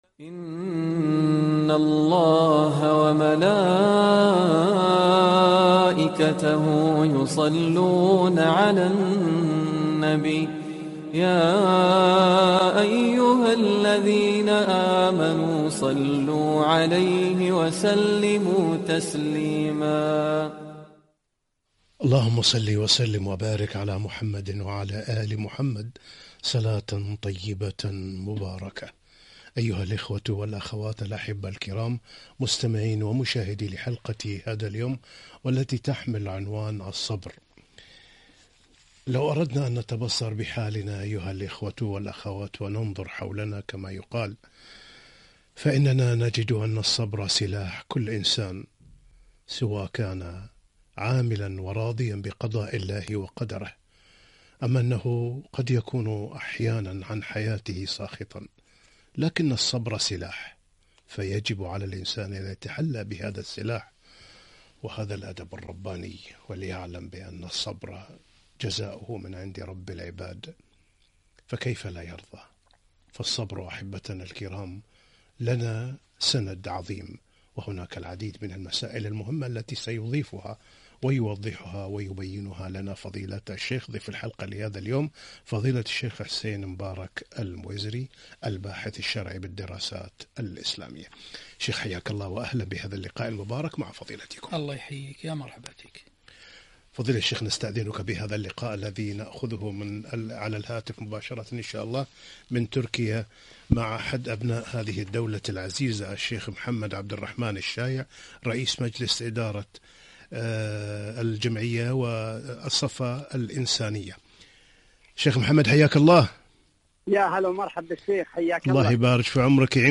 (والله يحب الصابرين) - لقاء إذاعي